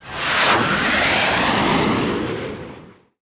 engine_flyby_003.wav